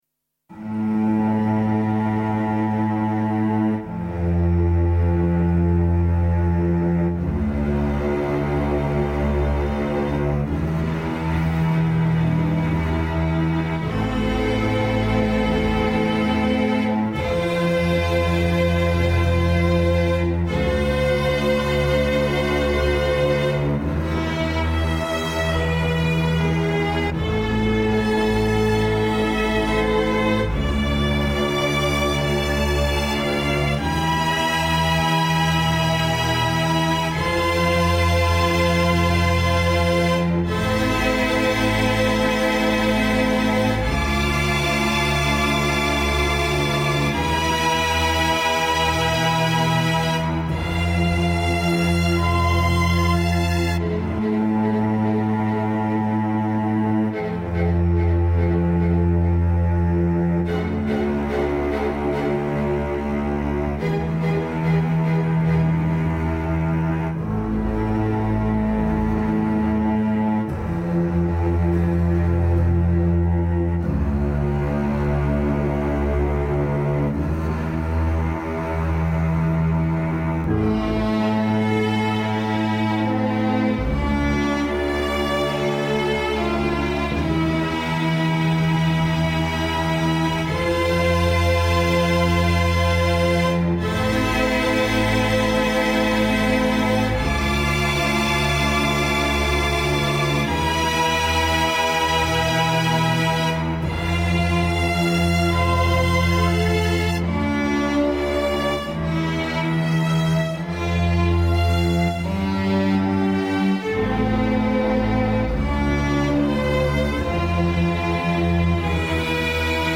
New theatre music score. Work in progress